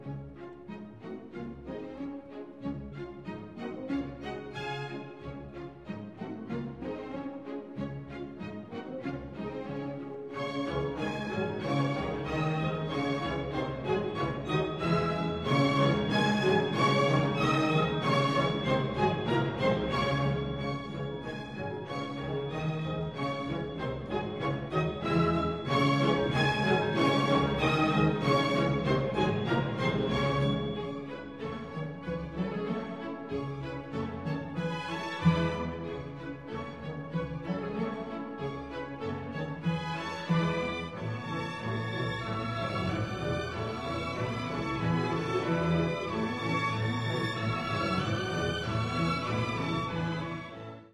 Lašské tance/6. Pílky-1889 (Filharmonie Brno, dir. František Jílek, Supraphon 1993/2006)